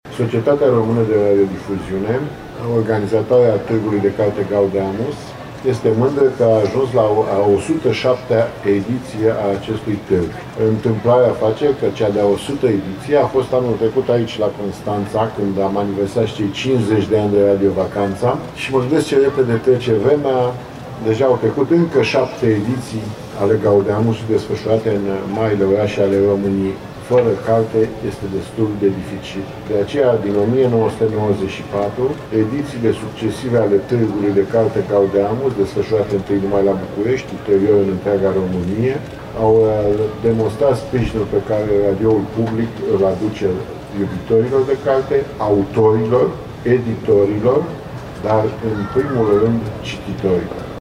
Iubitorii de lectură sunt aşteptaţi la Târgul de Carte „Gaudeamus Litoral”, organizat de Radio România şi aflat la cea de-a 10-a ediţie, deschis ieri în Piaţa Perla din Mamaia. La deschiderea evenimentului a fost prezent și Preşedintele Director General al Societăţii Române de Radiodifuziune, Georgică Severin: